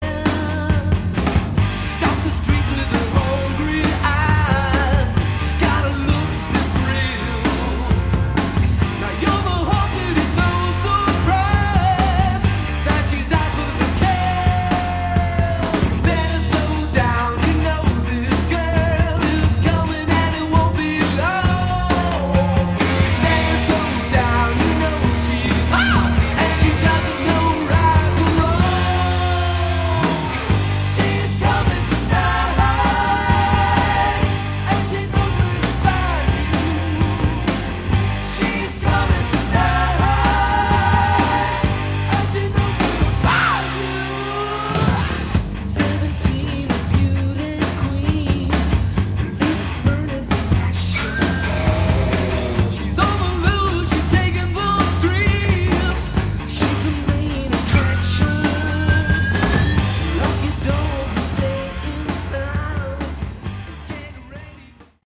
Category: Hard Rock
All Guitars
Drums, Percussion
Vocals, Screams
Bass, Keyboards, Vocals